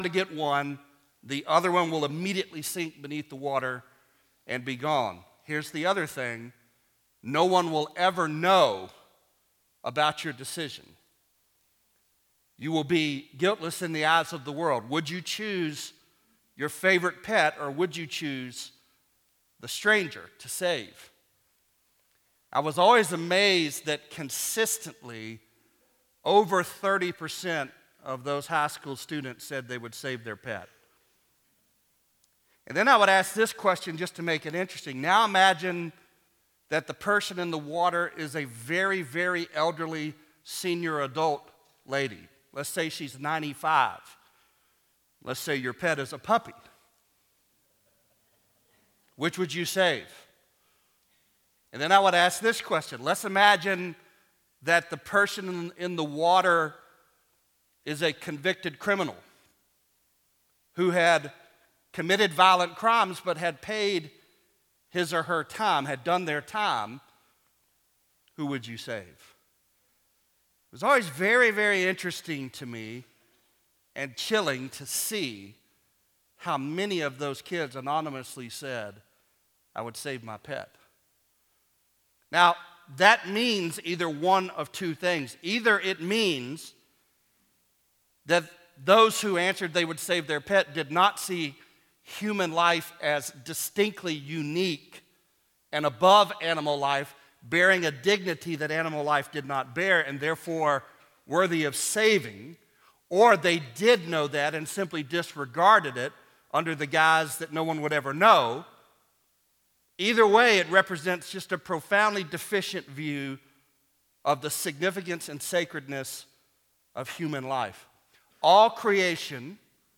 Genesis 1:1 (Preached on August 17, 2008, at First Baptist Church, Dawson, GA)